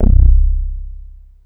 Super_BassStation_04(C1).wav